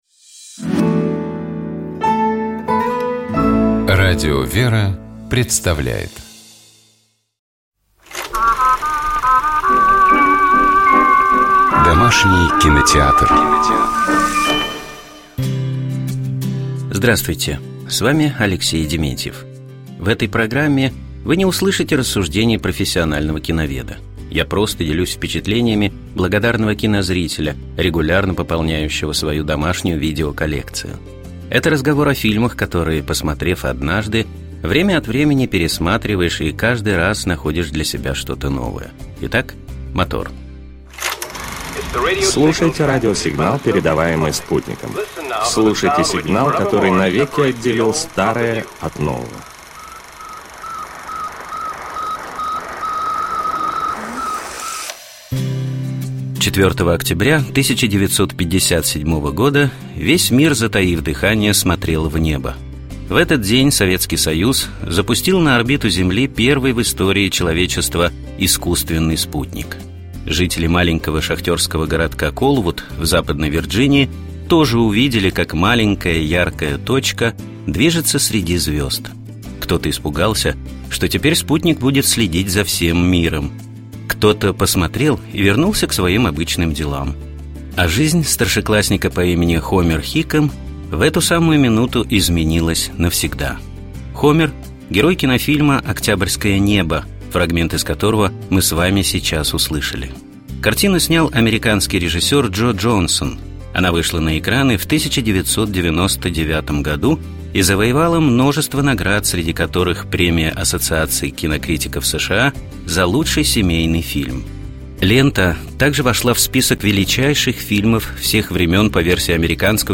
Хомер — герой кинофильма «Октябрьское небо», фрагмент из которого мы с вами сейчас услышали.